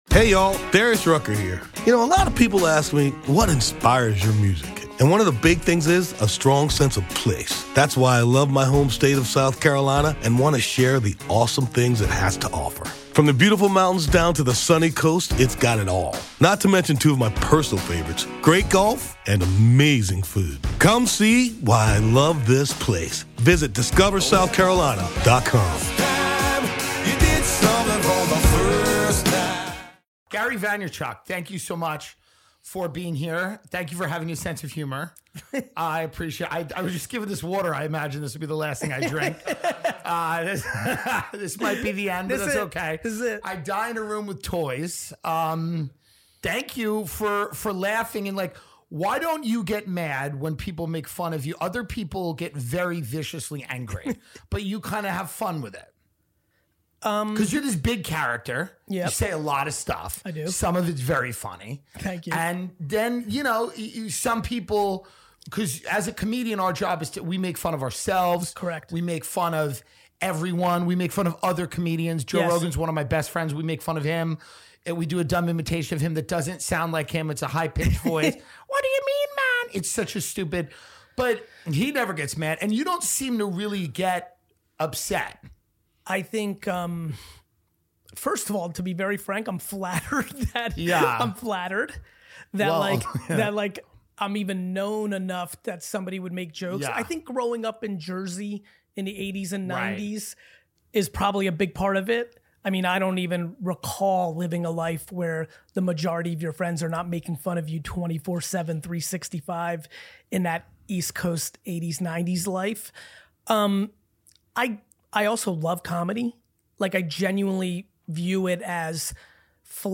- Tim Dillon sits down with Gary Vaynerchuk aka (Gary Vee) to talk about topics such as FTX, a possible rebrand for Poland, how the Weinstein Company could make a comeback, Vee Friends, and some talk about The Crypto Winter.